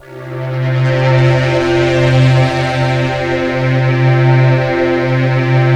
ALPS C3.wav